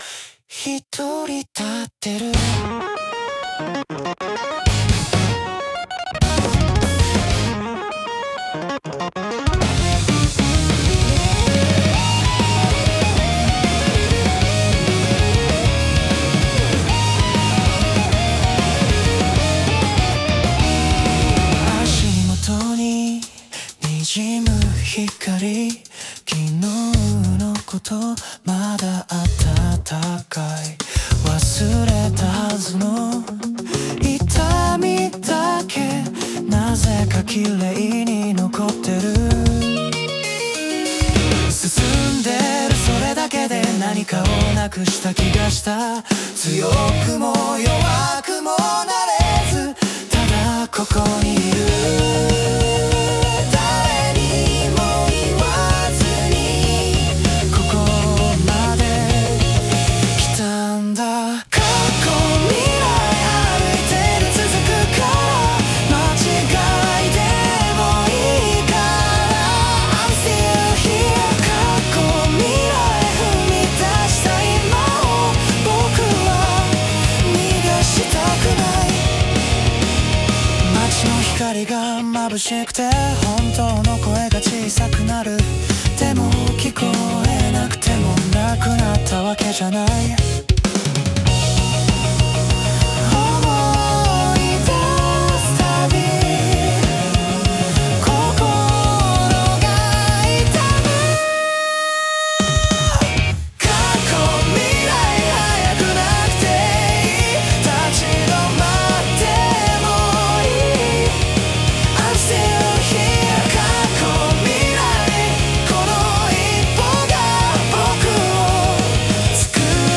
男性ボーカル
イメージ：シティーPOP,J-ROCK,男性ボーカル,かっこいい,優しい,切ない